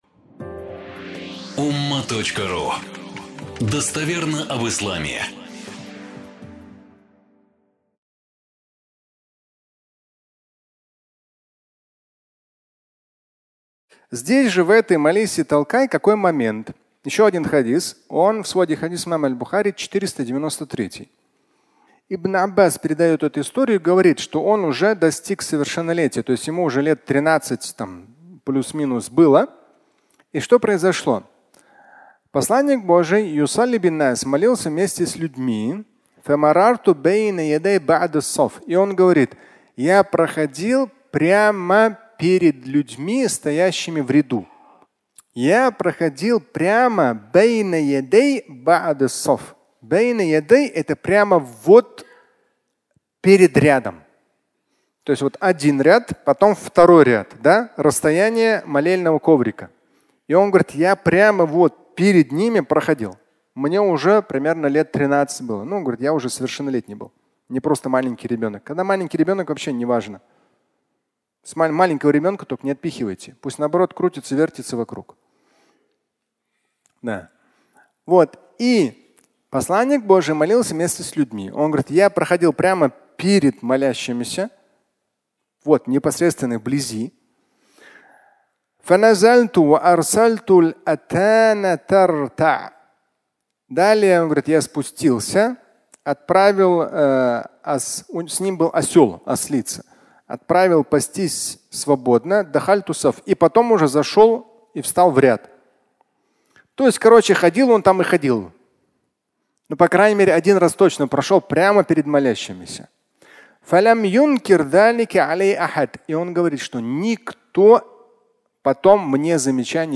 Фрагмент пятничной лекции
Пятничная проповедь